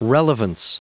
Prononciation du mot relevance en anglais (fichier audio)
Prononciation du mot : relevance